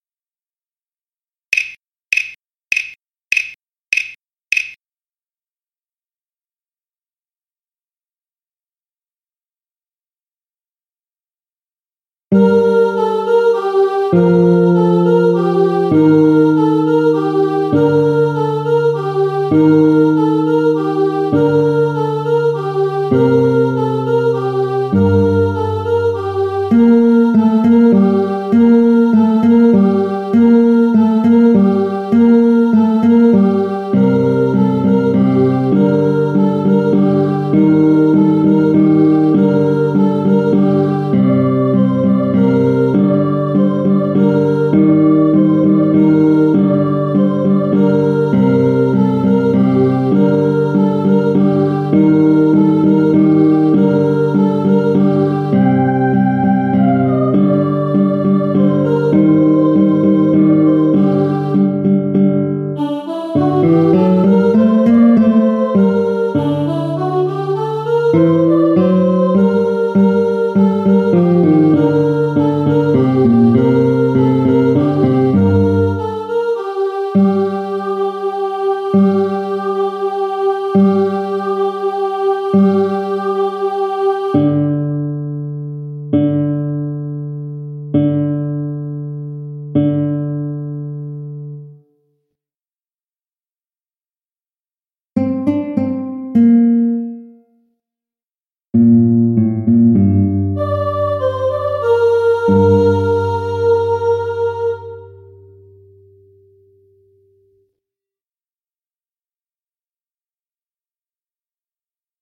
guitare + voix :
carol-of-the-bells-guitare-voix.mp3